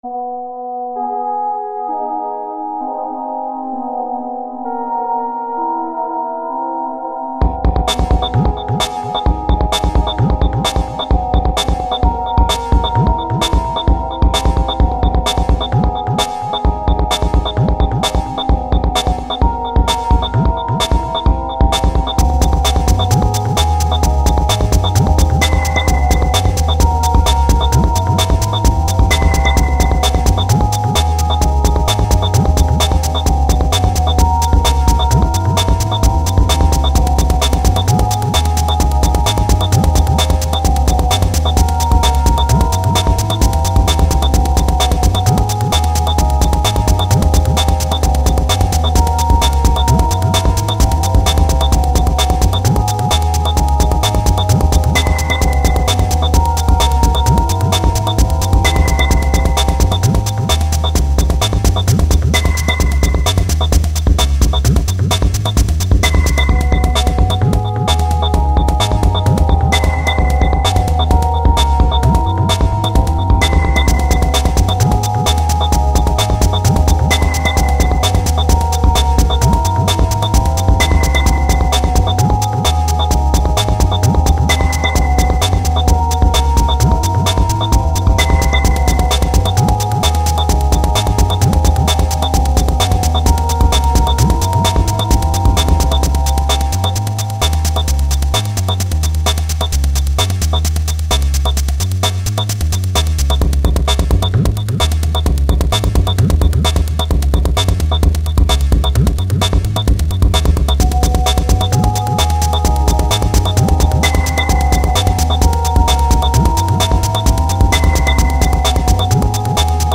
File under: Electronica / IDM
Generated using laptop computer technology